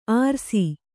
♪ ārsi